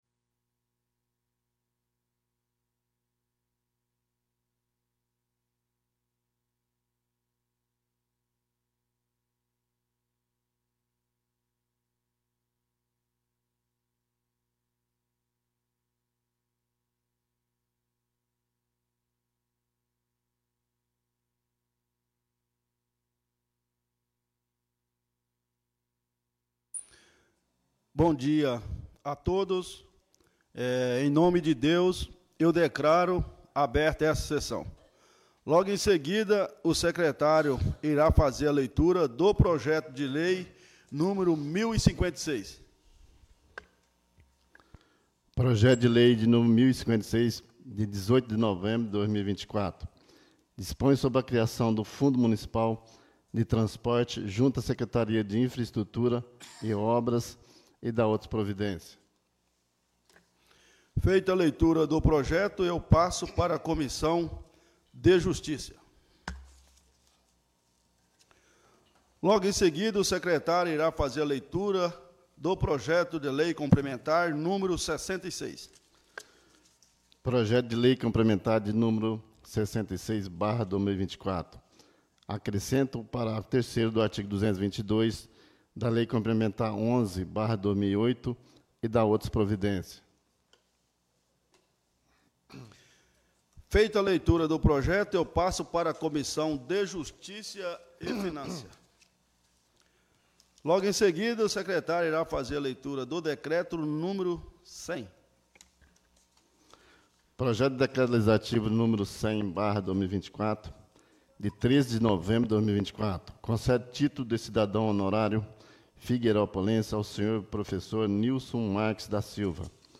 17° SESSÃO ORDINÁRIA DE 25 DE NOVEMBRO DE 2024